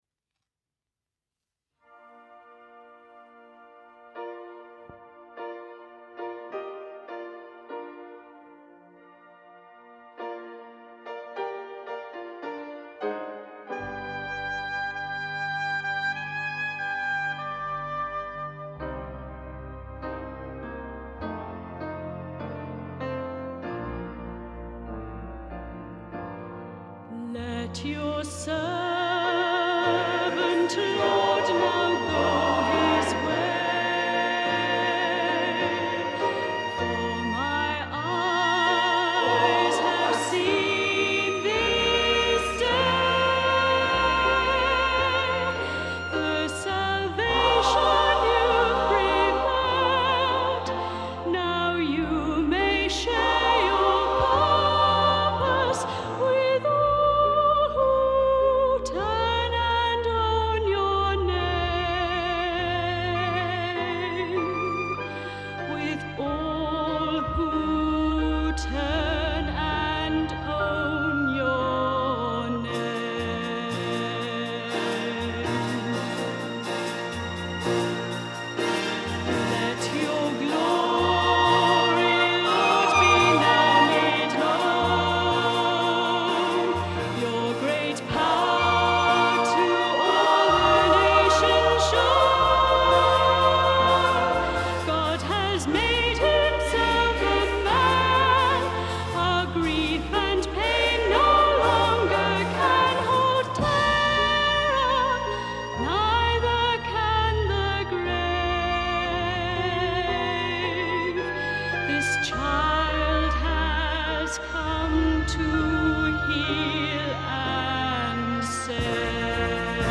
The first time we used drums in church – it was nearly the last.